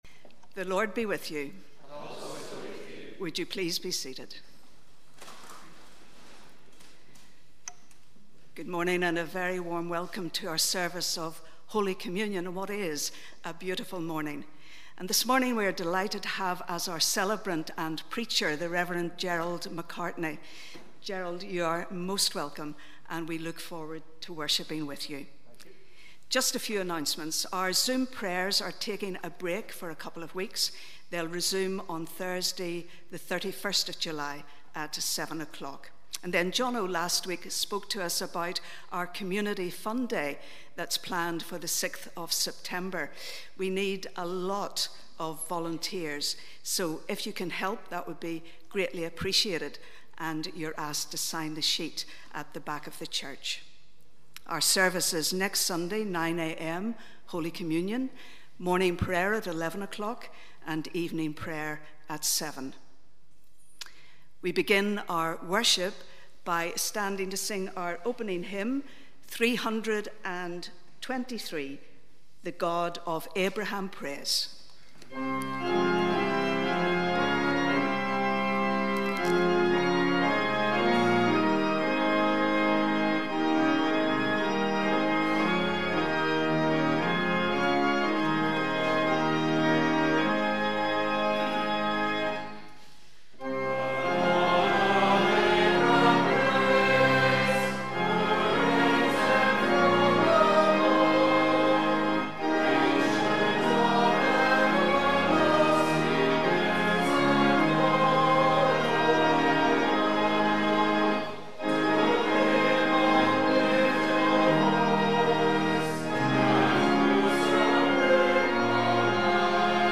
Welcome to our service of Holy Communion on the 4th Sunday after Trinity.